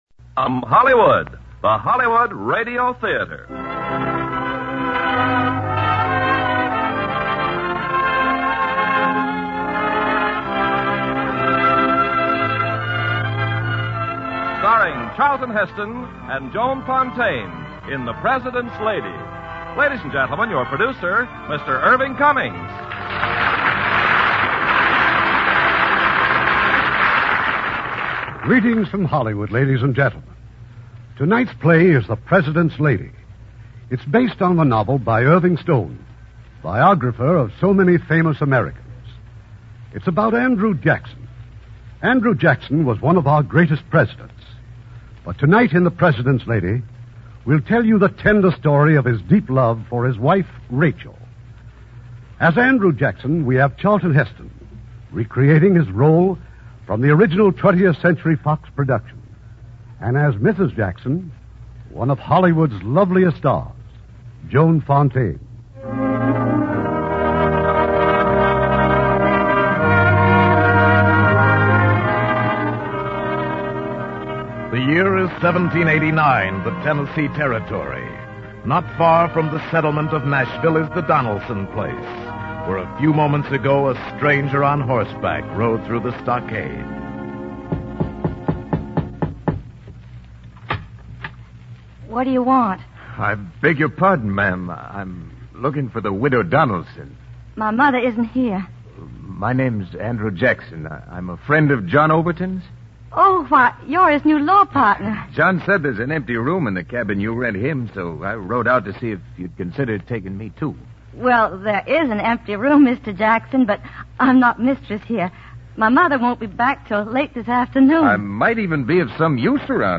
Lux Radio Theater Radio Show